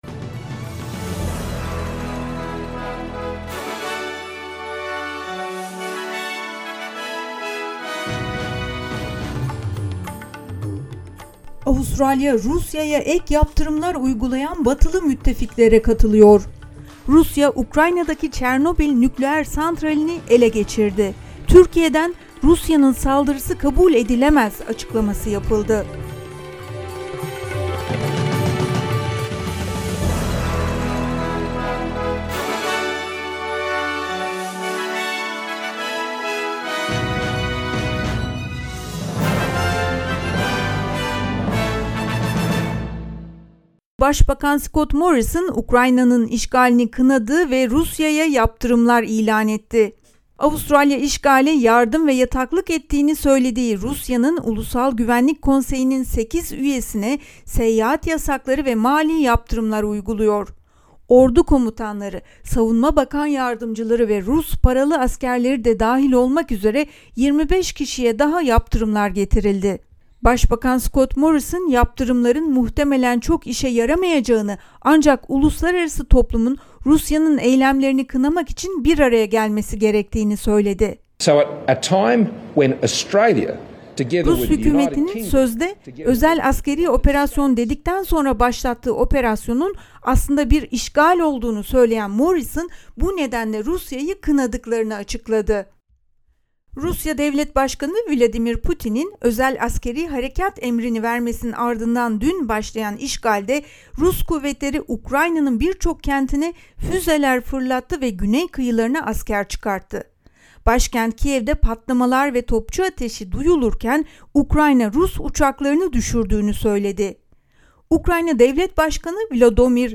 SBS Türkçe Haber Bülteni 25 Şubat